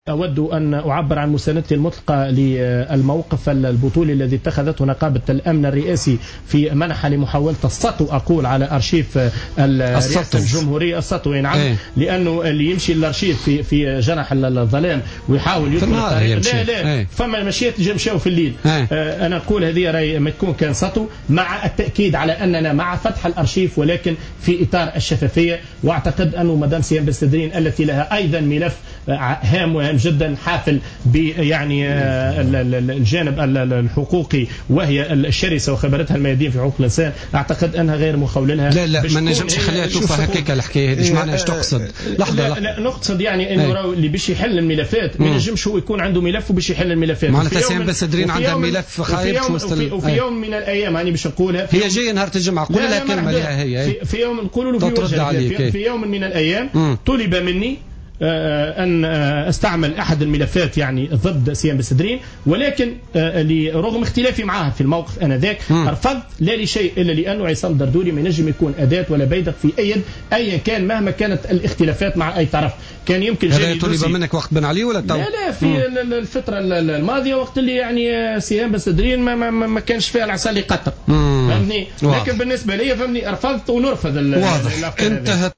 président de l’instance Vérité et Dignité lors de son passage sur les ondes de Jawhara FM.